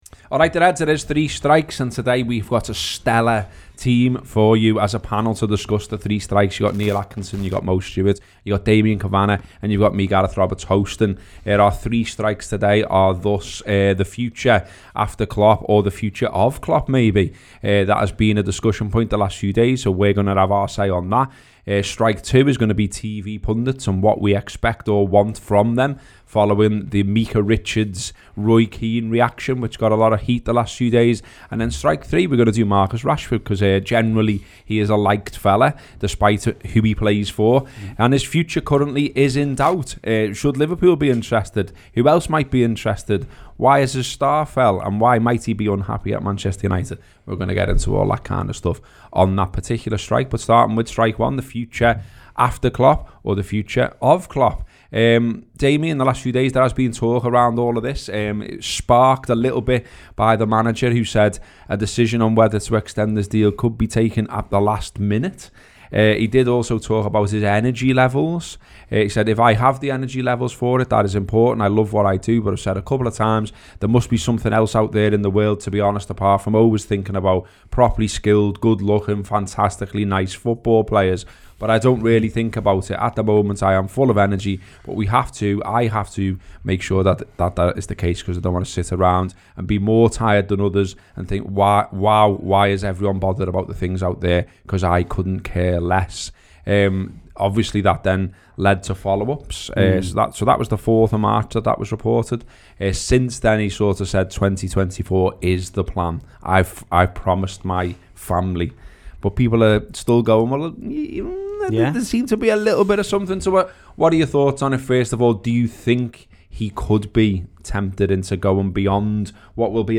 The three talking points the panel discuss are Jurgen Klopp’s future, what we expect/want from TV pundits and what’s next for Marcus Rashford.